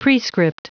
Prononciation du mot prescript en anglais (fichier audio)
Prononciation du mot : prescript